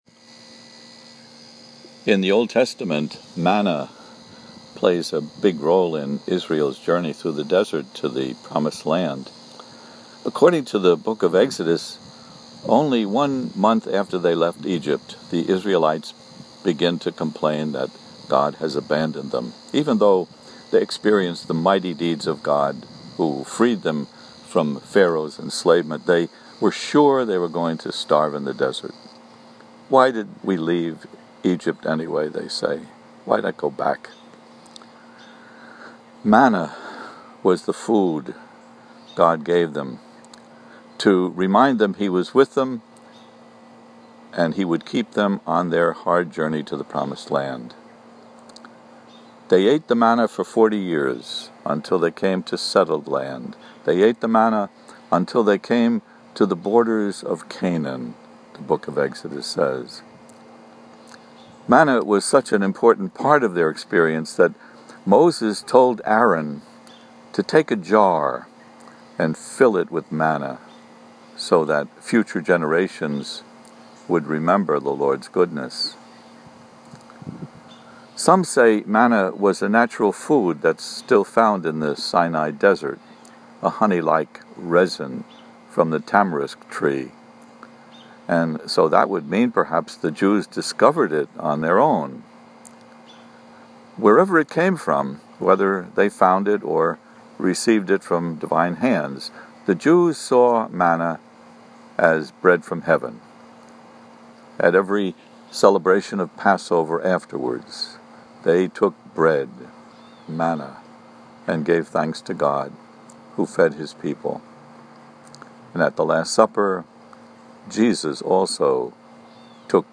For an audio of the homily see below: